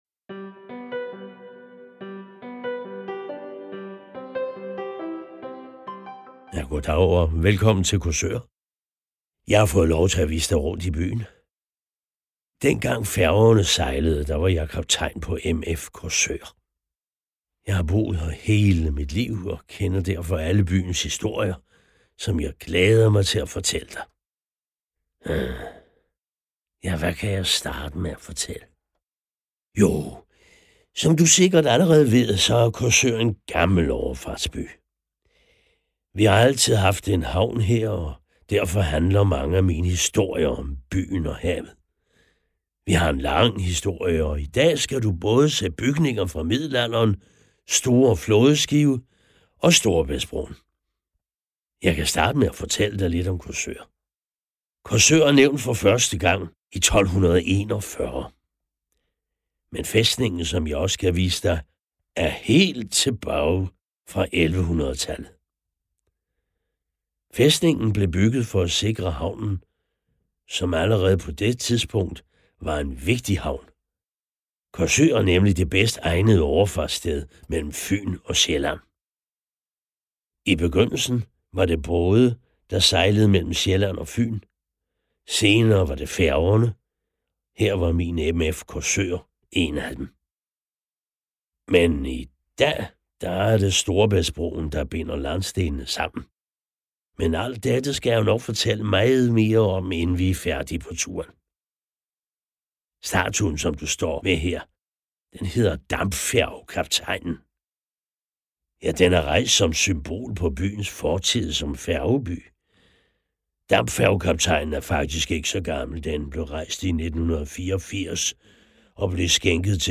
PodWalk (Danmark)
En fortælling om Korsør, fortalt af den gamle kaptajn.